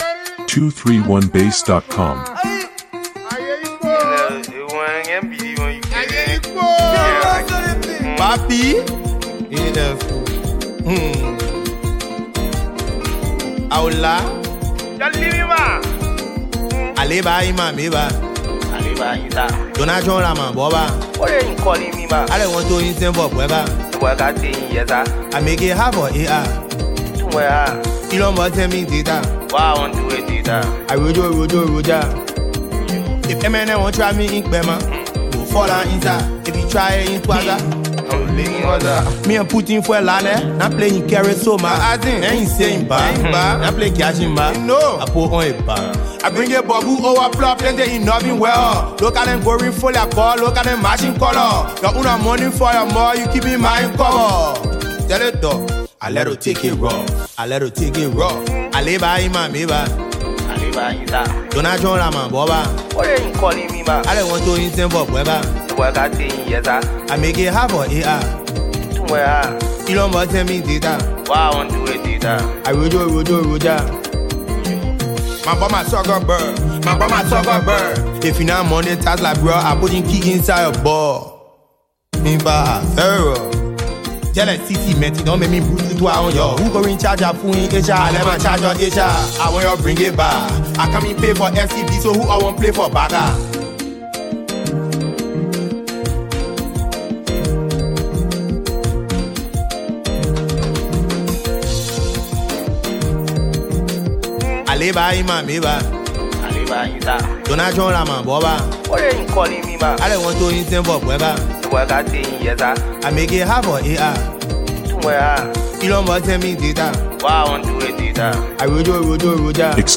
With its ridiculously catchy hooks and playful bars